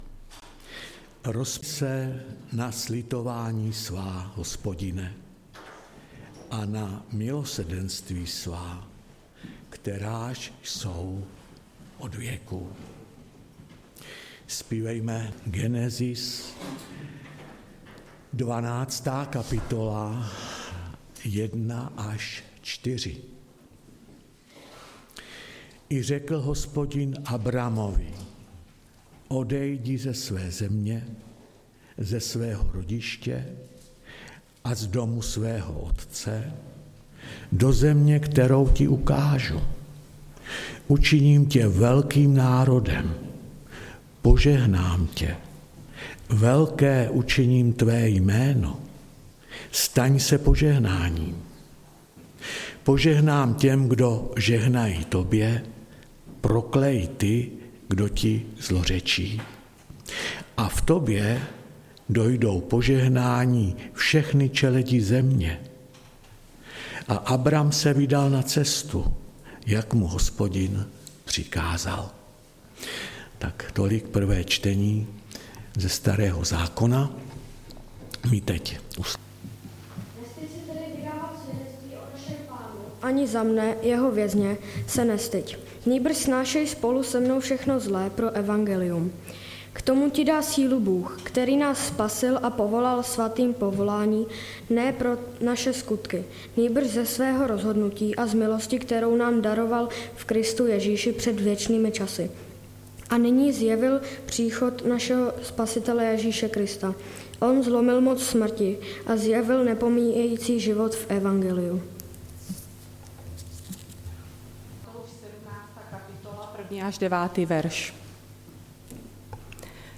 Nedělní bohoslužba